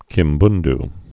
(kĭm-bnd)